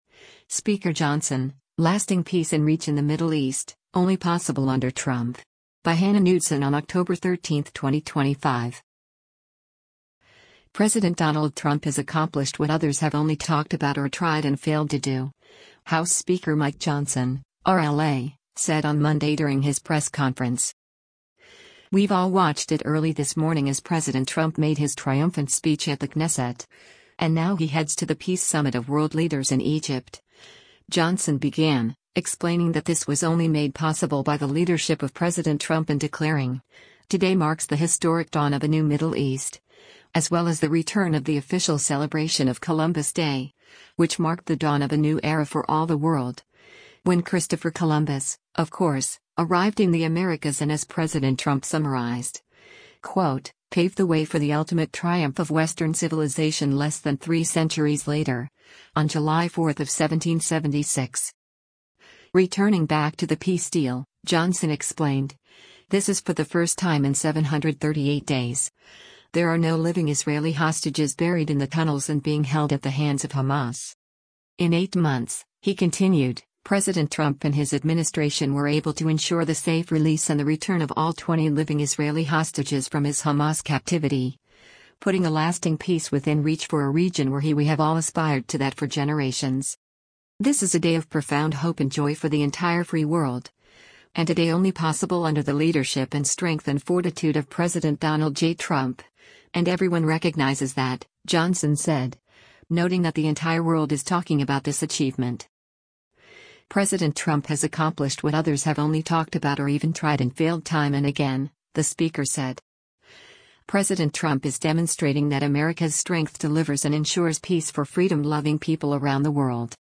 President Donald Trump has accomplished what others have only talked about or tried and failed to do, House Speaker Mike Johnson (R-LA) said on Monday during his press conference.